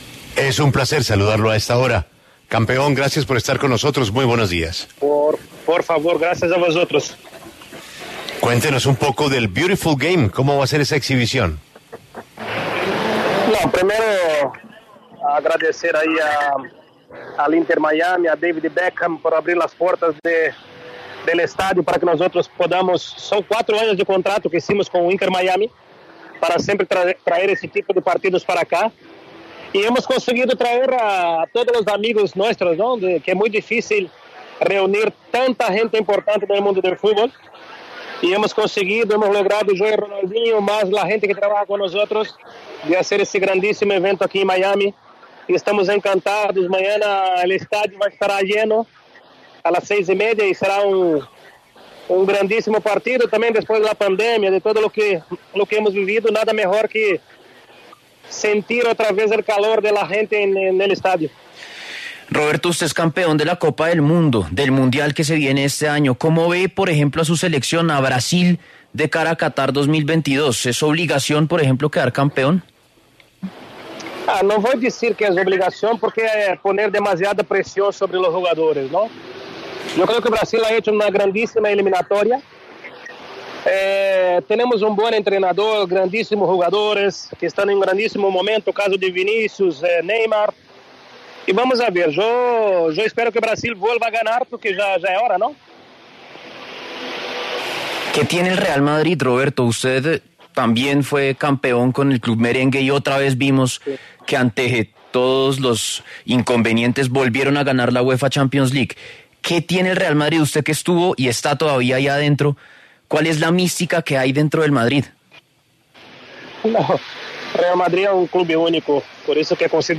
Roberto Carlos, leyenda del fútbol de Brasil, habló en La W sobre el anuncio del partido de exhibición llamado The Beautiful Game, que será en el sur de la Florida.